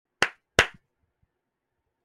Double Clap Best